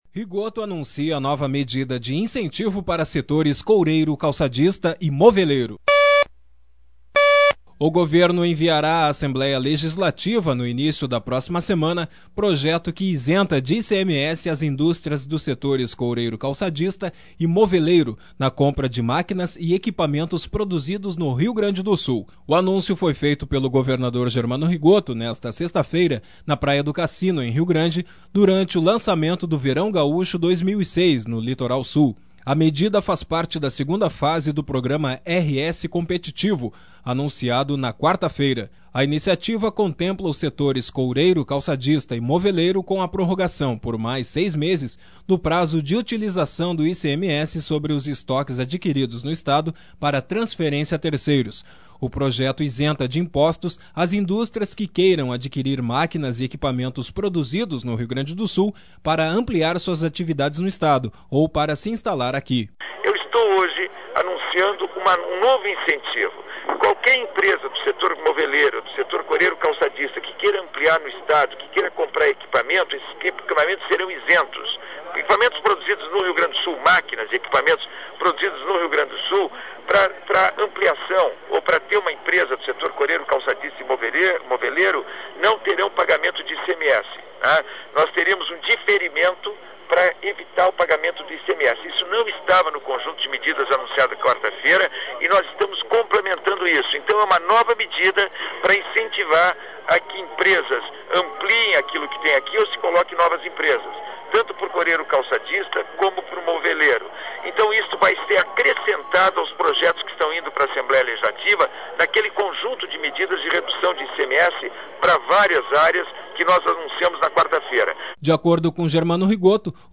O governo enviará à Assembléia Legislativa no início da próxima semana projeto que isenta de ICMS as indústrias dos setores coureiro-calçadista e moveleiro na compra de máquinas e equipamentos produzidos no Rio Grande do Sul. sonora: governador Germano